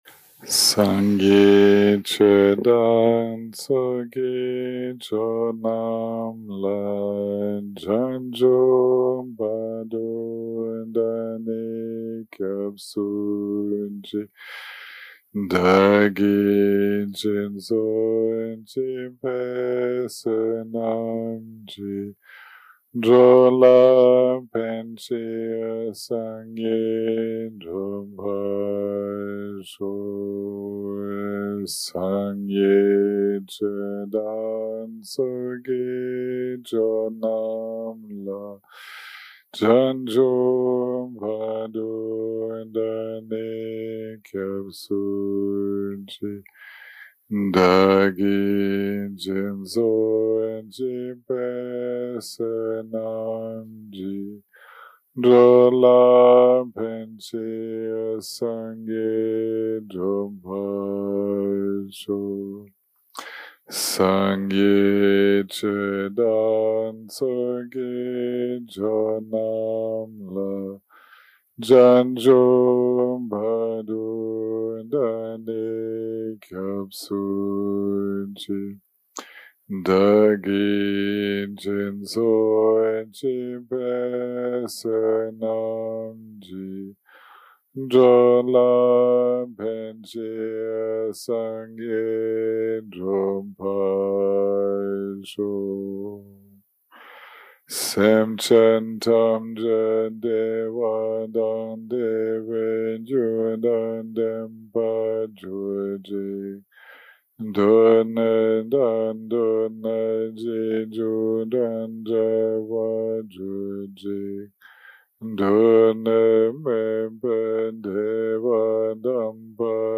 day 7 - recording 24 - Afternoon - Dharma Talk + Guided Meditation - Self Liberated View, Meditation & Five Aspects of the Mind